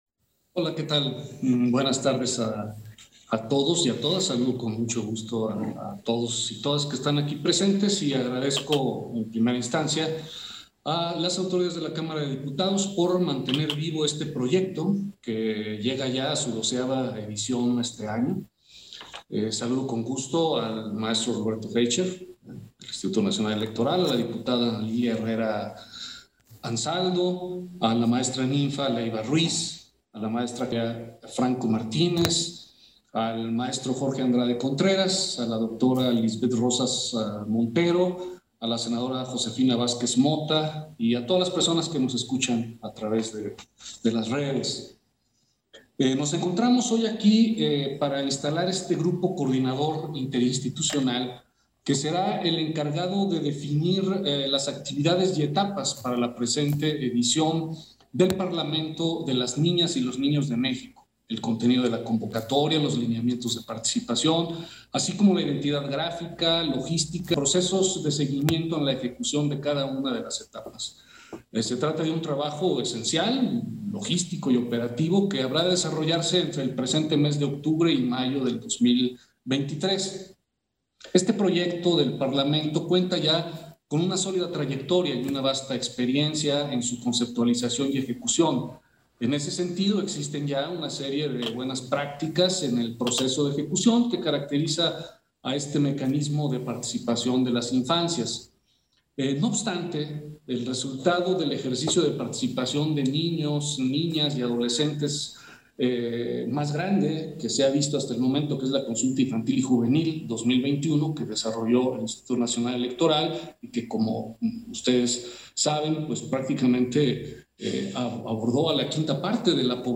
Intervención de Martín Faz, en la sesión de instalación del grupo coordinador interinstitucional para la organización del 12º Parlamento de las niñas y los niños de México